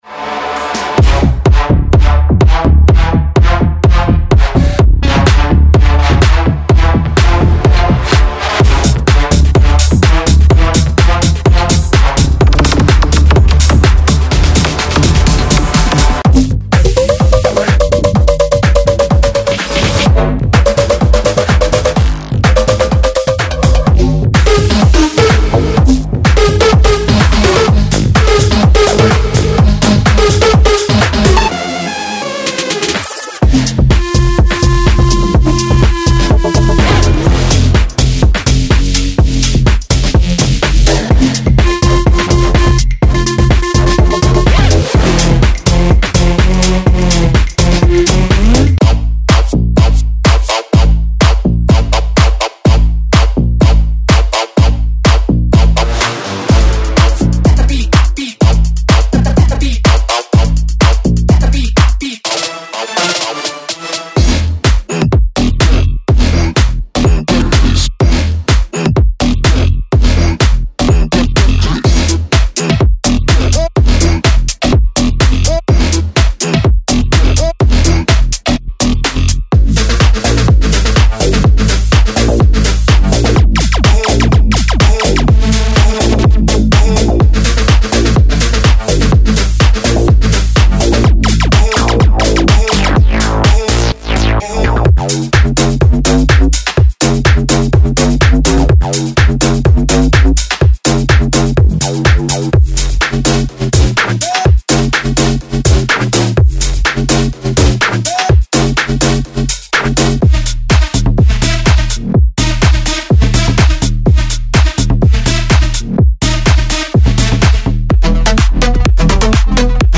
デモサウンドはコチラ↓
Genre:Bass House